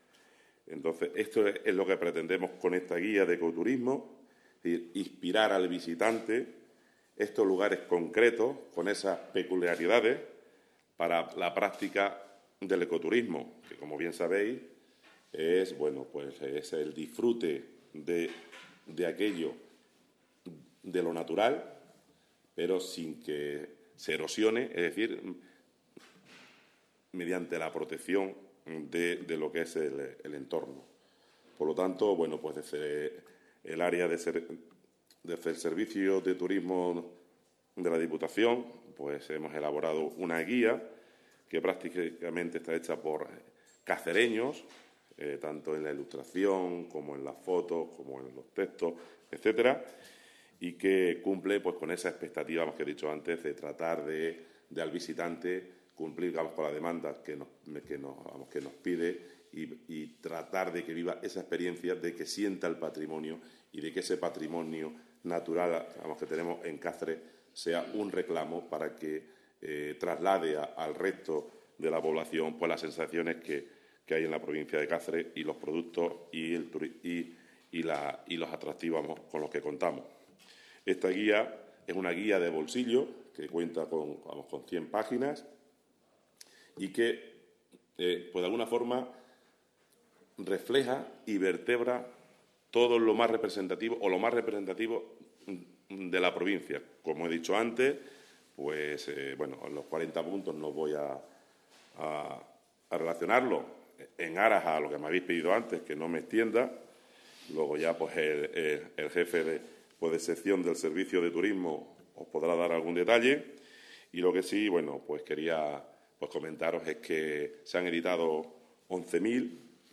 CORTES DE VOZ
El diputado de Turismo, Deportes y Juventud, Álvaro Arias Rubio, ha presentado este martes en rueda de prensa la guía ’40 puntos para la práctica del ecoturismo en la provincia de Cáceres’.